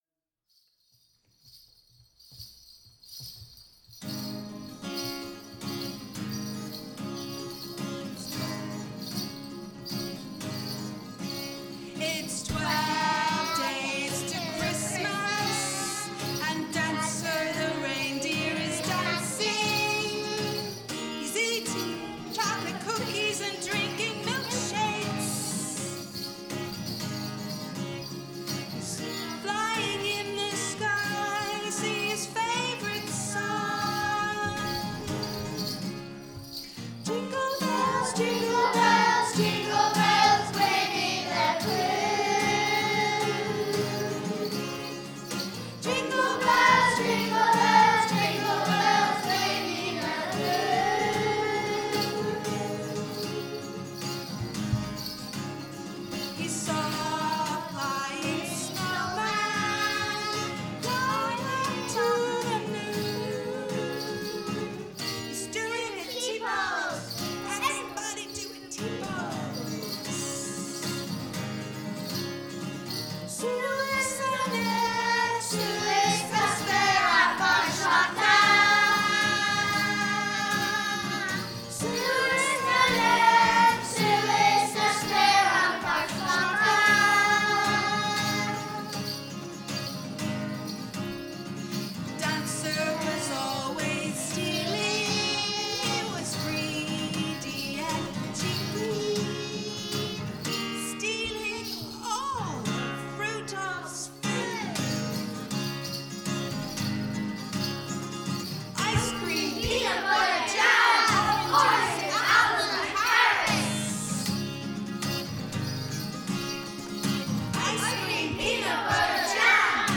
Recording Day at Ballina Arts Centre